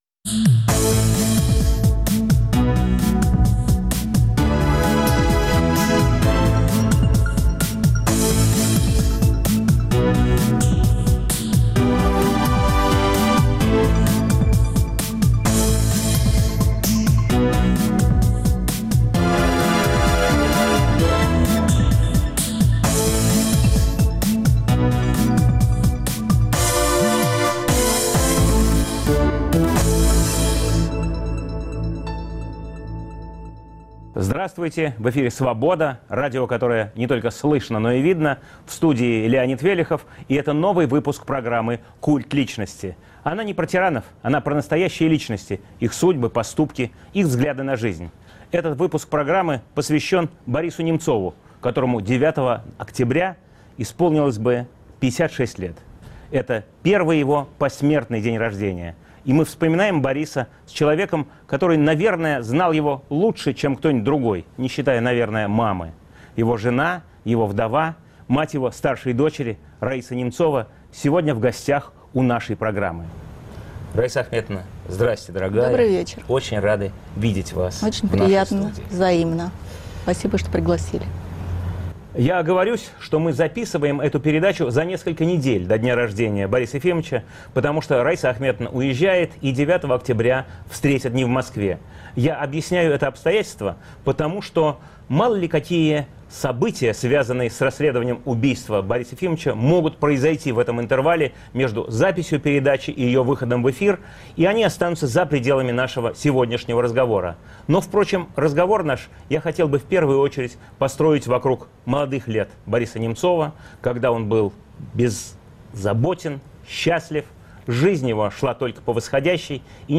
В программе также принимает участие Григорий Явлинский.